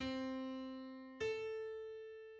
En opadgående stor sekst kan for eksempel gå fra c1 til a1: